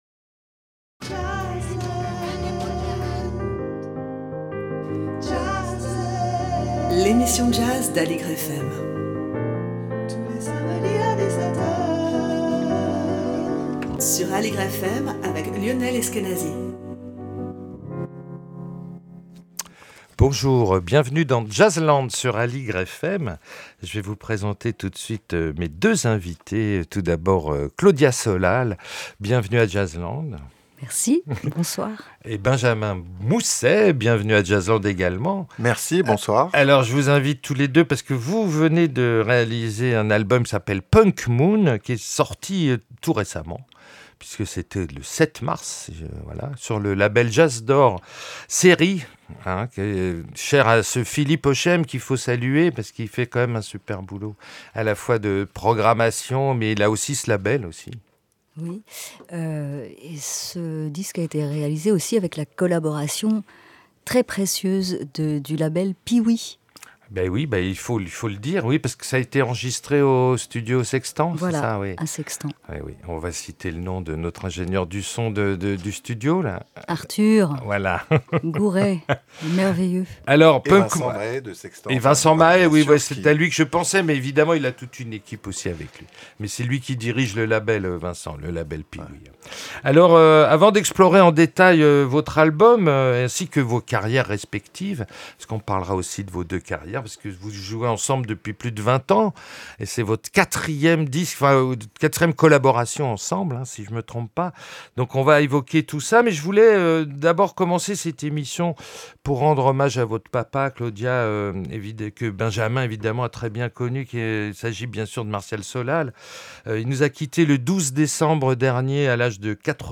Emission sur l'actualité du jazz avec la présence de deux invités.